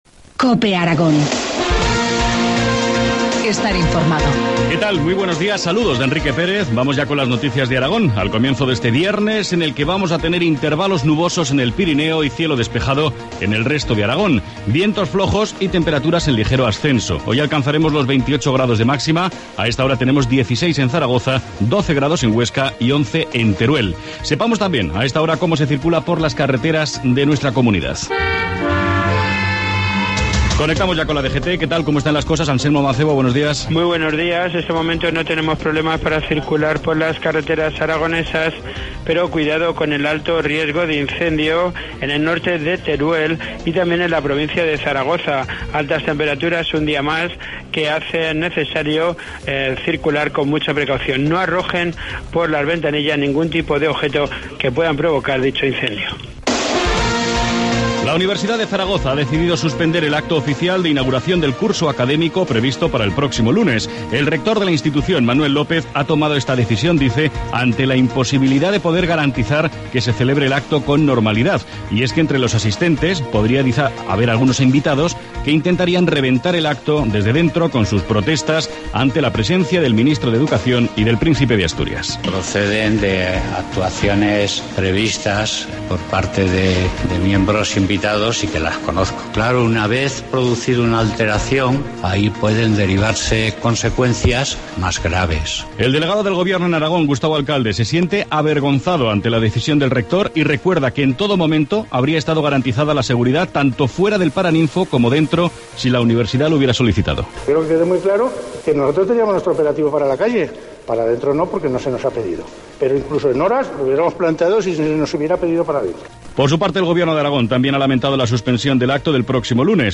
Informativo matinal, viernes 20 septiembre, 2013, 7,25 horas